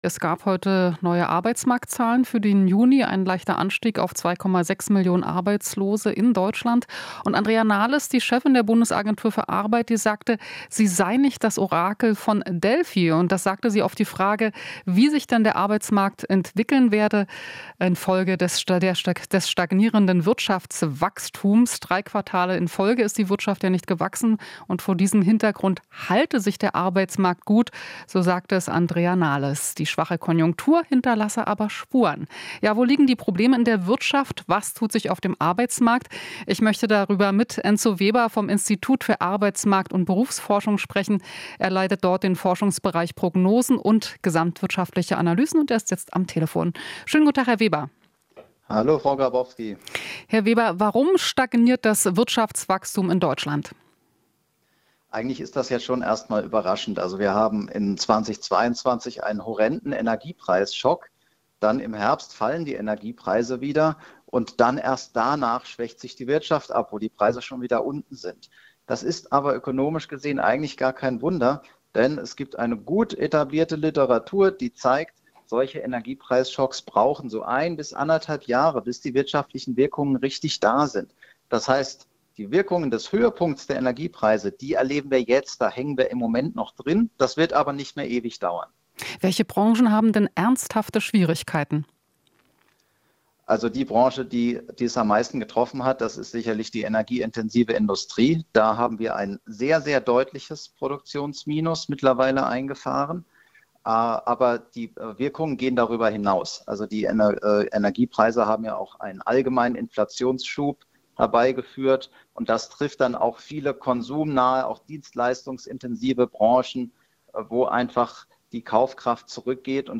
Experte: Arbeitsmarkt grundsätzlich in robuster Verfassung - 01.08.2023